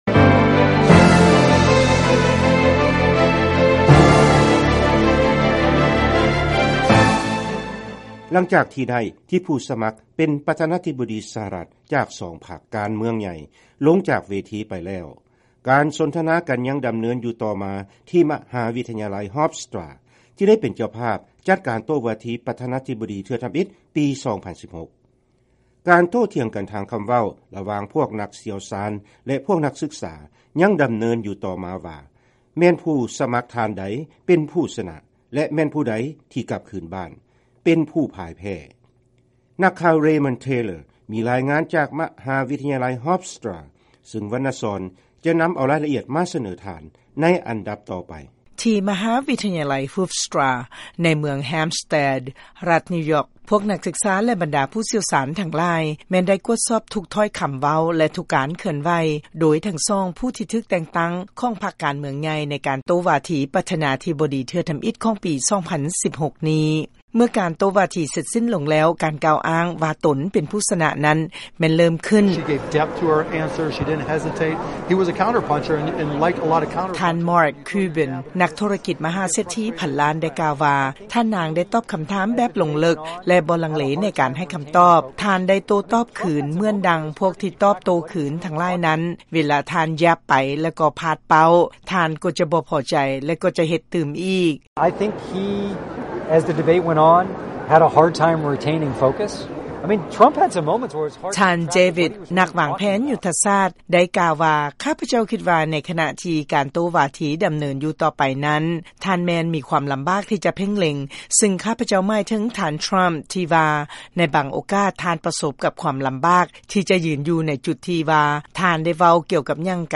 ຟັງລາຍງານຂອງການໂຕ້ວາທີຜູ້ສະໝັກປະທານາທິບໍດີສະຫະລັດ